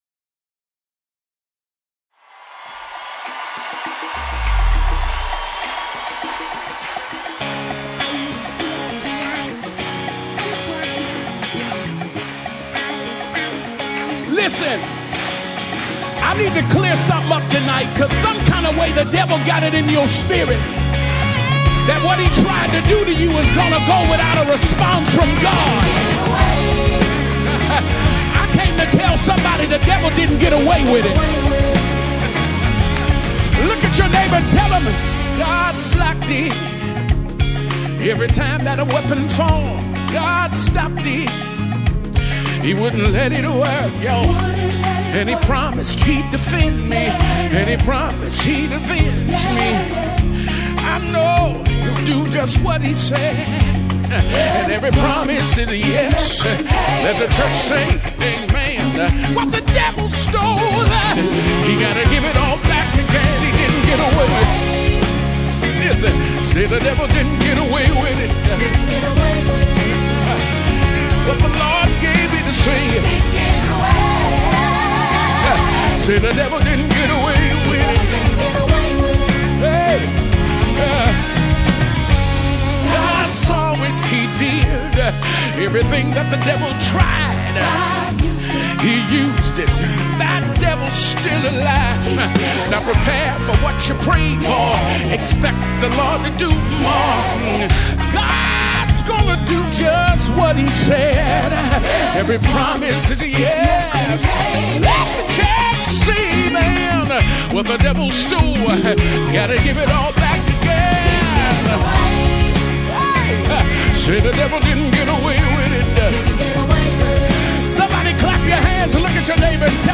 International Gospel Songs
a beautiful and glorious melody